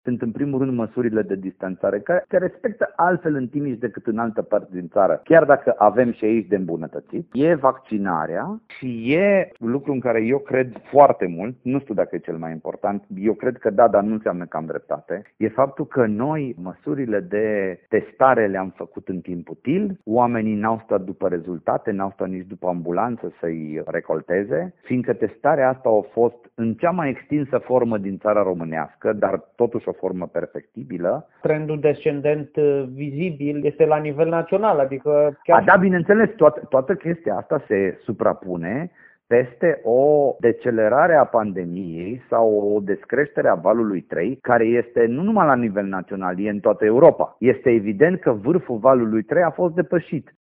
Directorul DSP Timiș explică scăderea susținută a infectărilor în județ